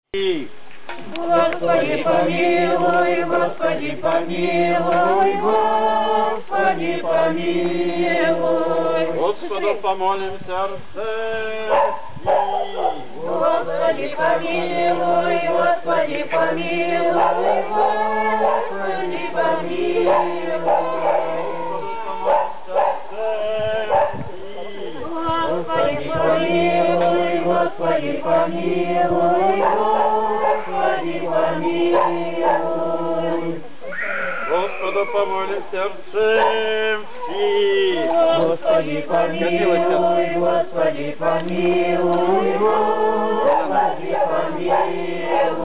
Празднование  Преображения  Господня
Крестный  ход